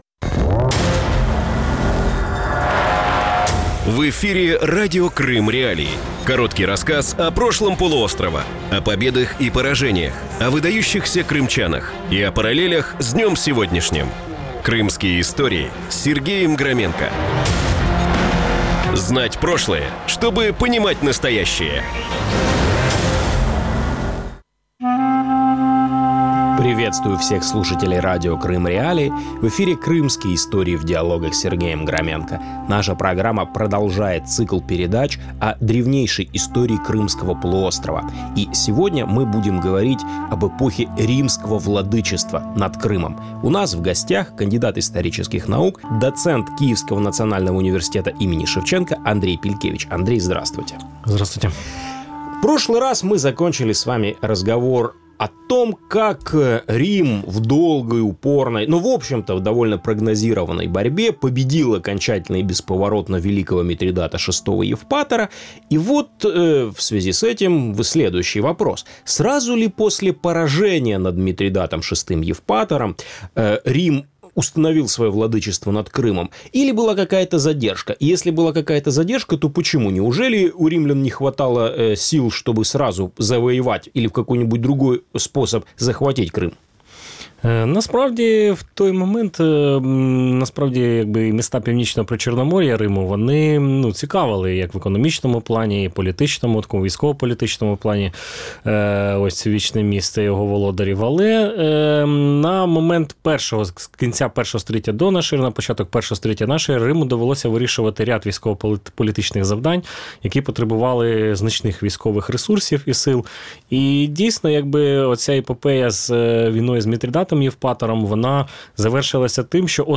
Новый цикл Крымских.Историй в диалогах рассказывает об истории Крыма с древнейших времен до наших дней.
Эфир можно слушать Крыму в эфире Радио Крым.Реалии (105.9 FM), а также на сайте Крым.Реалии